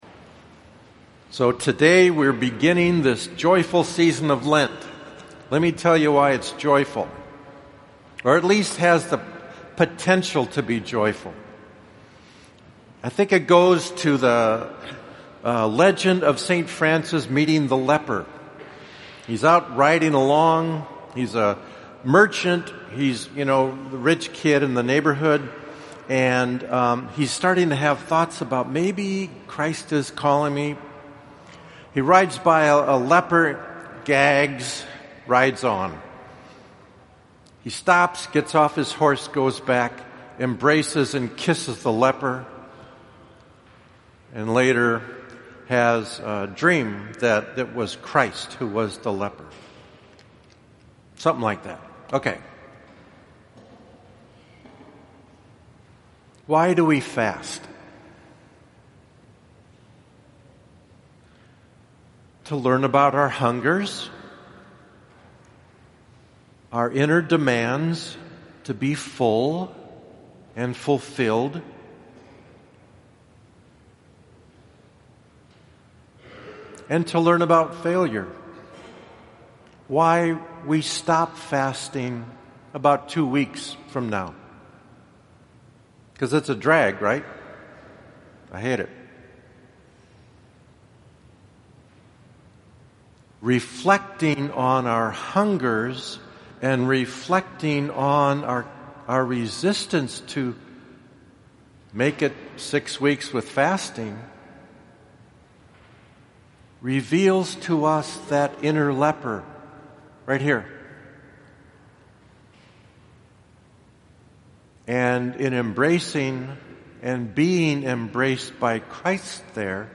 Ash Wednesday Homily